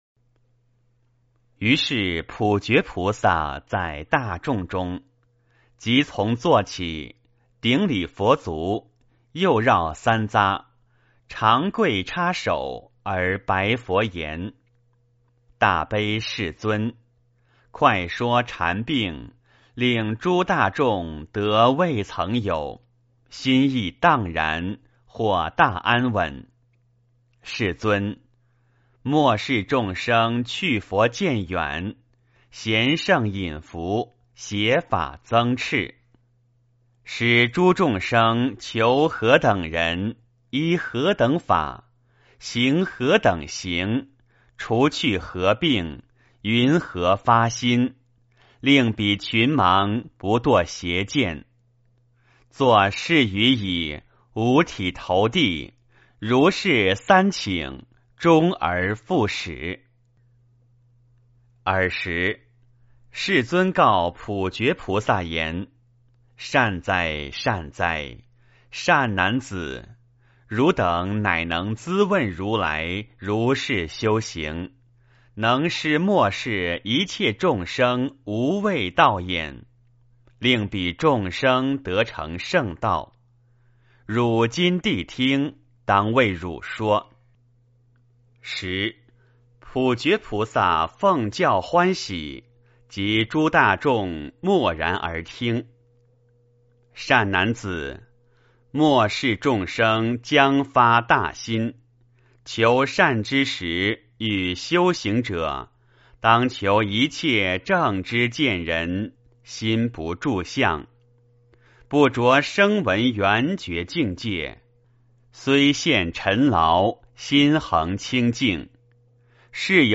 圆觉经-10普觉菩萨 - 诵经 - 云佛论坛